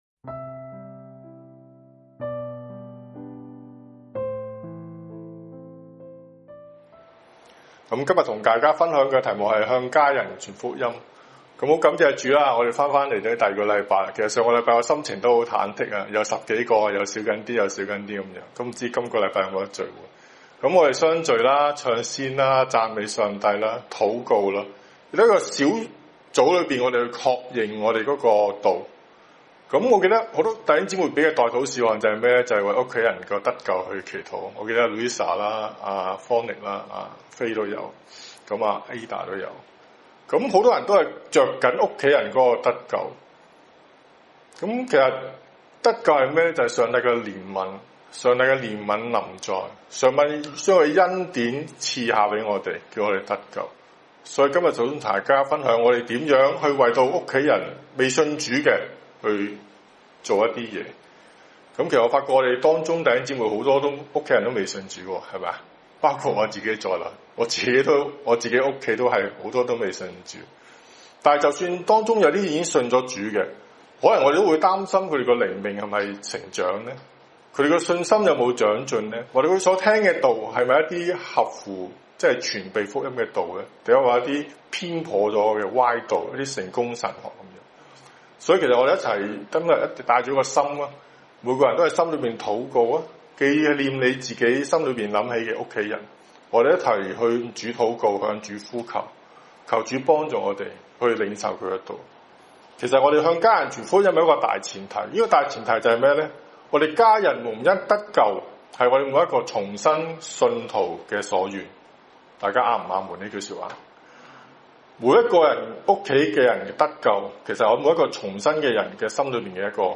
向家人传福音[10月11日香港主日崇拜]